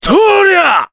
Gau-Vox_Attack4_jp.wav